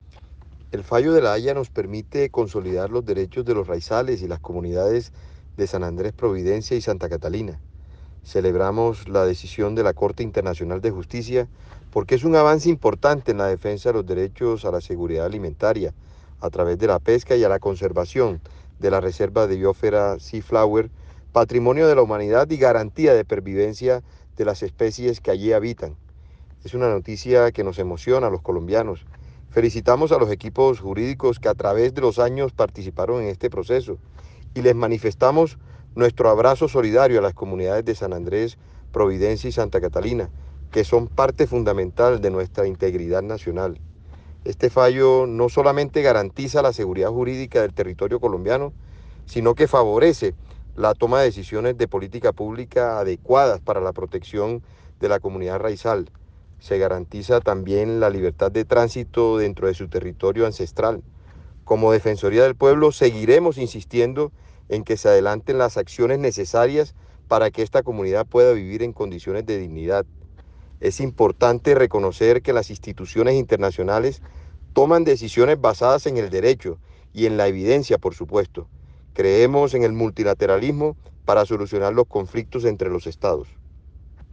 Descargue y escuche el pronunciamiento del Defensor del Pueblo